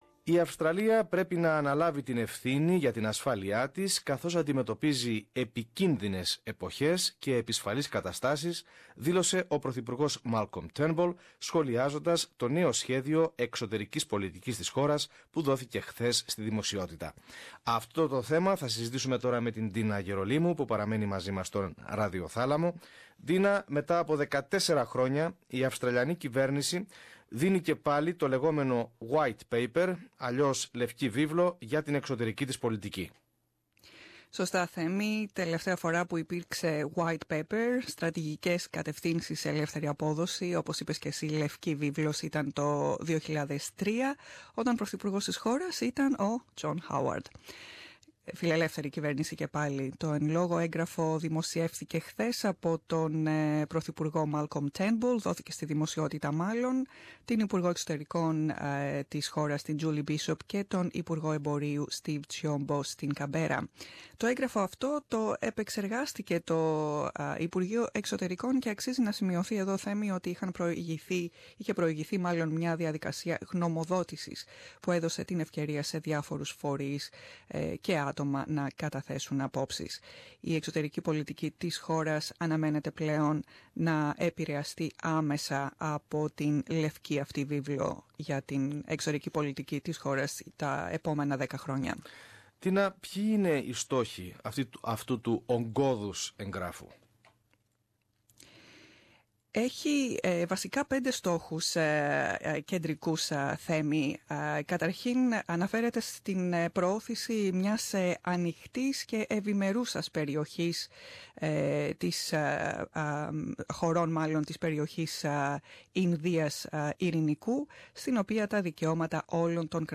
SBS Ελληνικά